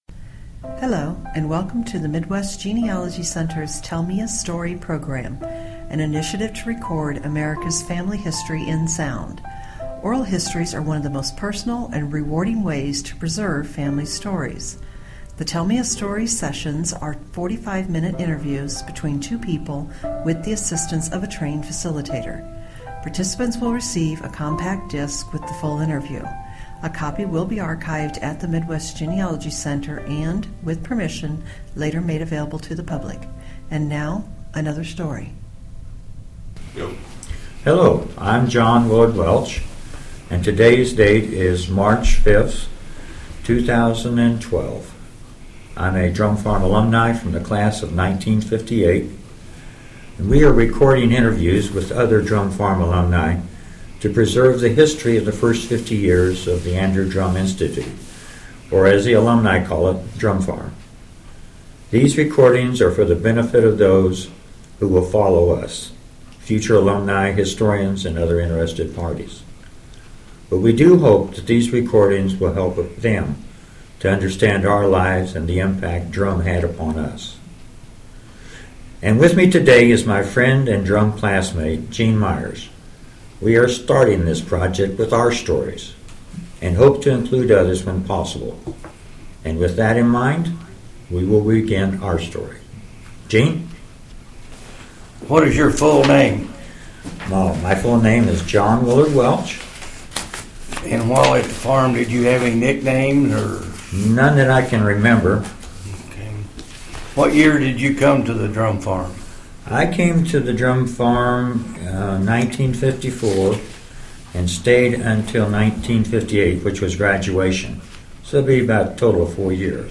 Drumm Institute Oral Histories